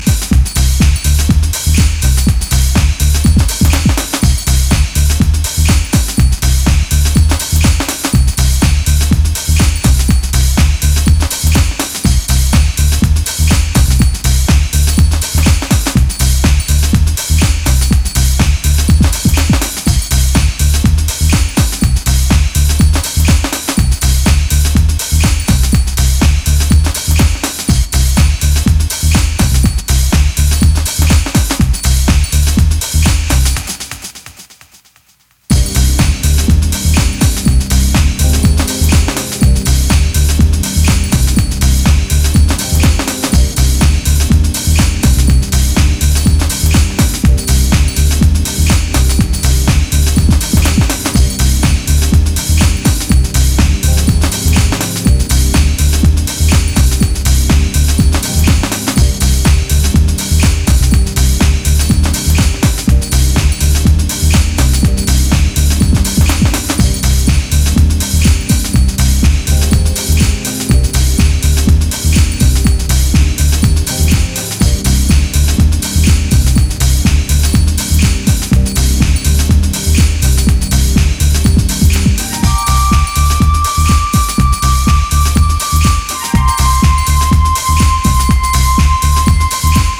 ジャンル(スタイル) HOUSE CLASSIC / DEEP HOUSE